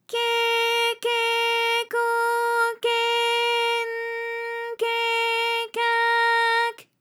ALYS-DB-001-JPN - First Japanese UTAU vocal library of ALYS.
ke_ke_ko_ke_n_ke_ka_k.wav